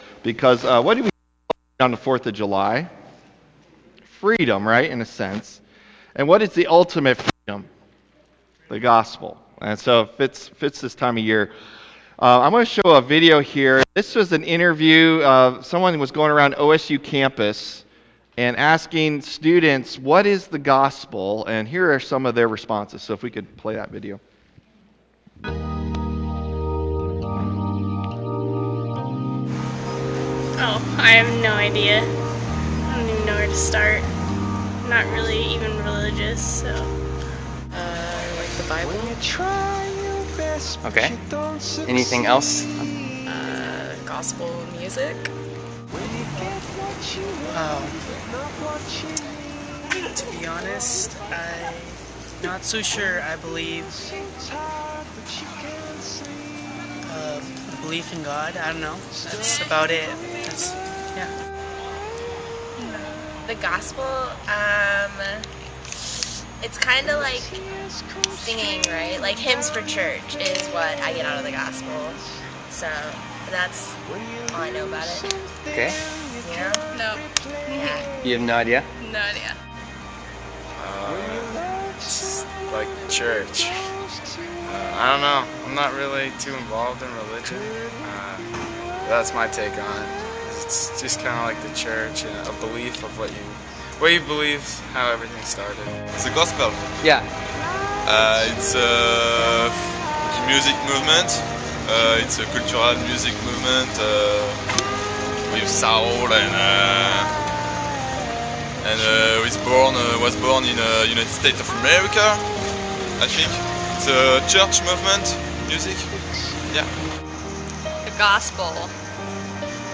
July 2 Sermon | A People For God